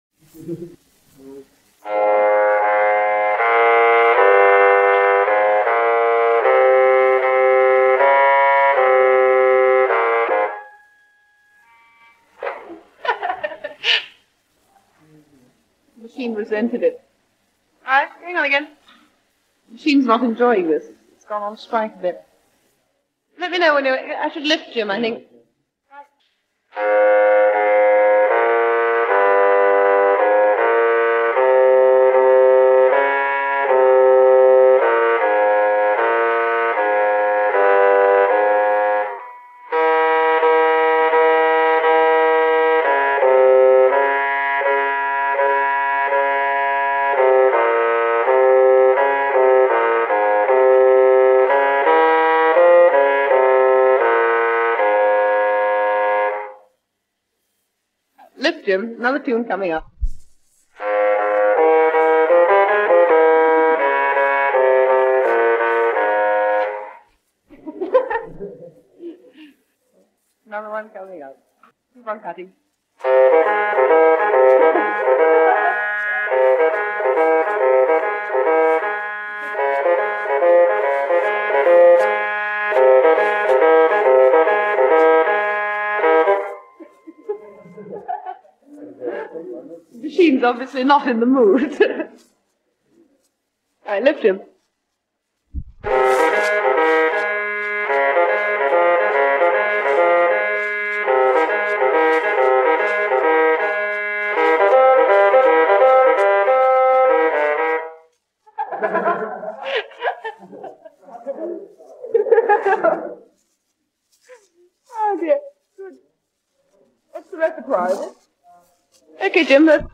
For my 3rd-yr classes – First ever recording of computer music
Though not so “groovy” and catchy it represents the beginning of a new era for many modern artists.
Restoring-the-first-recording-of-computer-music.mp3